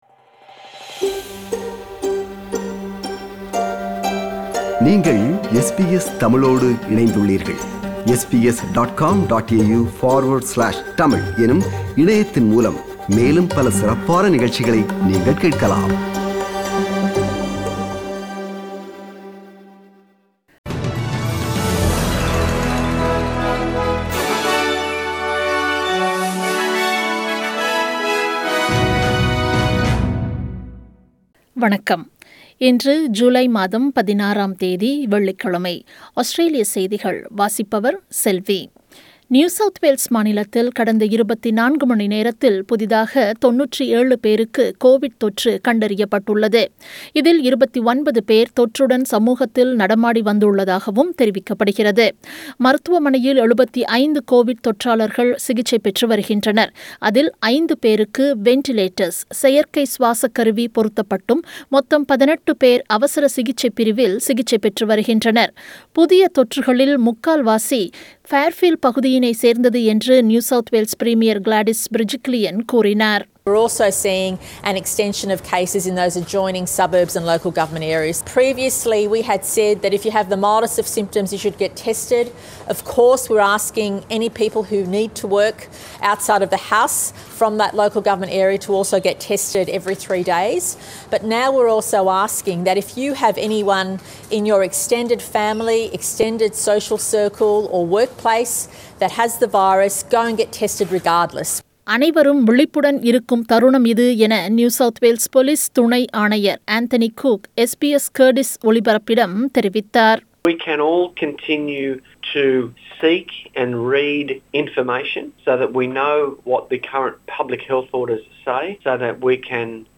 Australian News